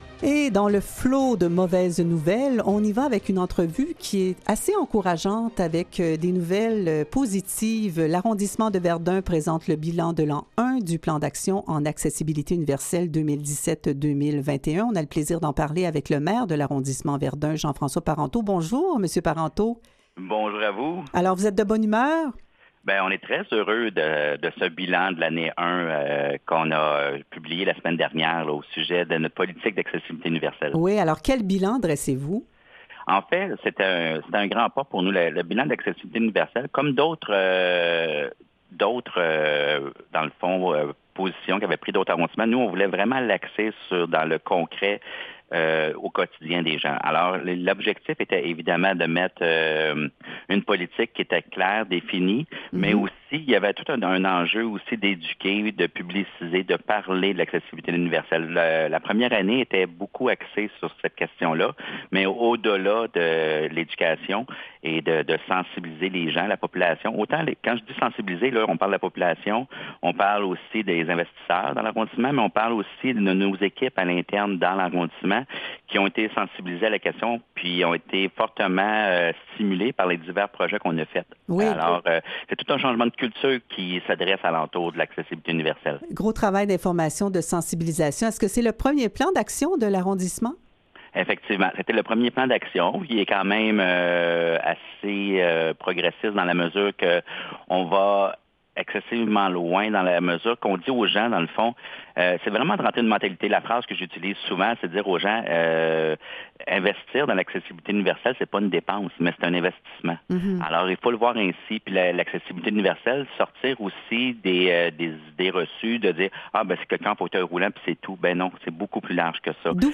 LES ENTREVUES DU JOUR
Avec Jean-François Parenteau, maire d'arrondissement. —